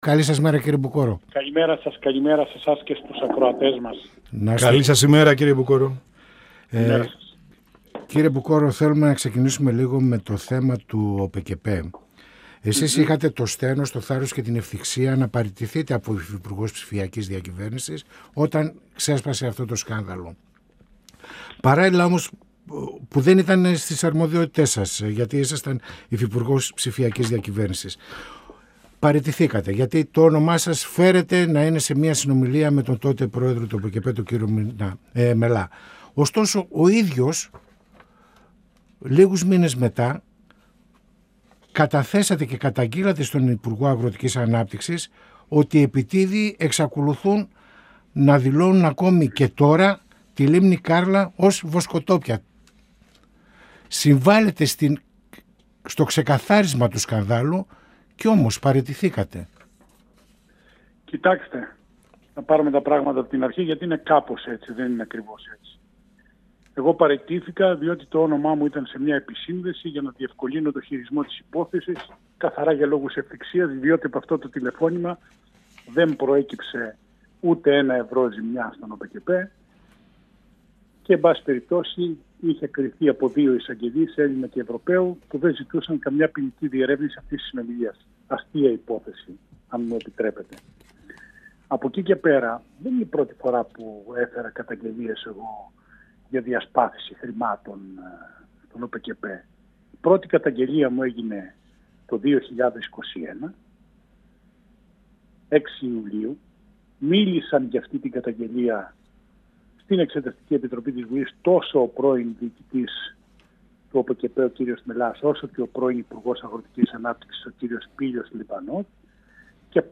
Στις εξελίξεις και τις αποκαλύψεις στο μέγα θέμα διαφθοράς του σκανδάλου του ΟΠΕΚΕΠΕ, καθώς και την αναγκαία καταβολή επιδοτήσεων, ενισχύσεων και αποζημιώσεων στους αγρότες και στους κτηνοτρόφους αναφέρθηκε ο Βουλευτής της ΝΔ Χρήστος Μπουκώρος, μιλώντας στην εκπομπή «Πανόραμα Επικαιρότητας» του 102FM της ΕΡΤ3.